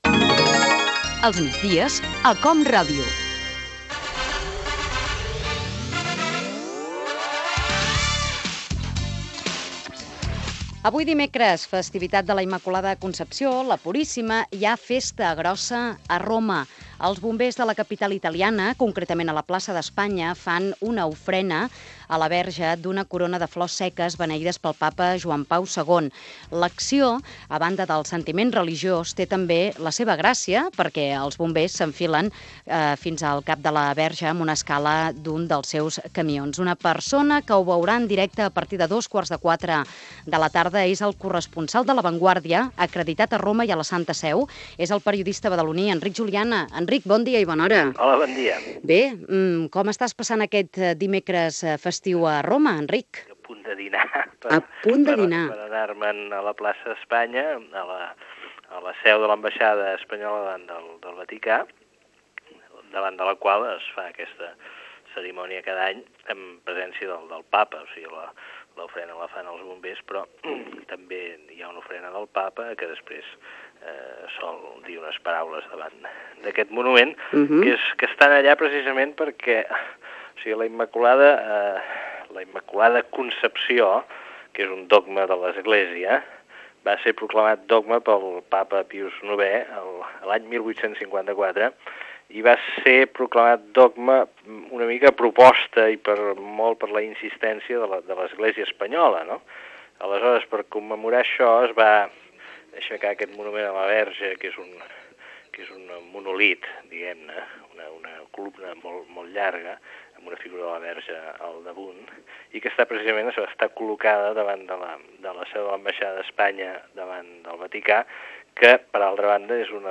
Data, fragment d'una conversa amb Enric Juliana, corresponsal de "La Vanguardia" a Roma i el Vaticà.
FM